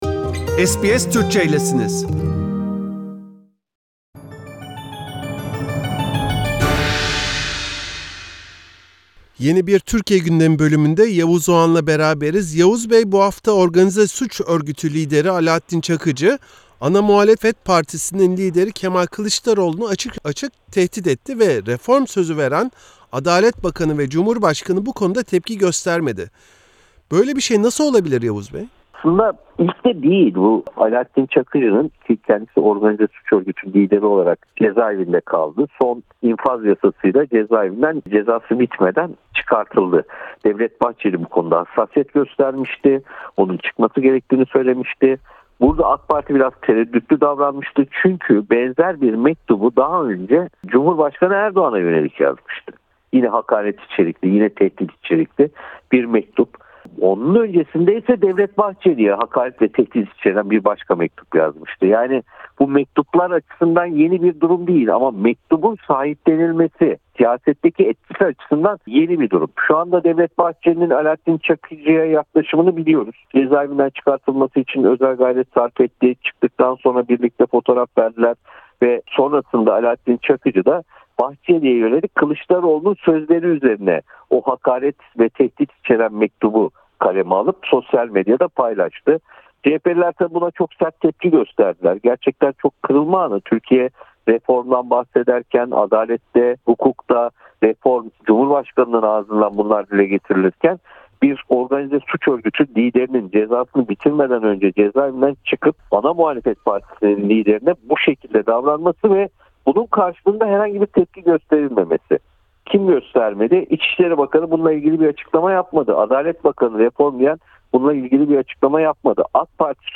Gazeteci Yavuz Oğhan, SBS Türkçe için Alaattin Çakıcı’nın CHP lideri Kemal Kılıçdaroğlu’na yönelik sözlerini, Merkez Bankası’nın faiz kararını ve COVID-19’da en son durumu değerlendirdi.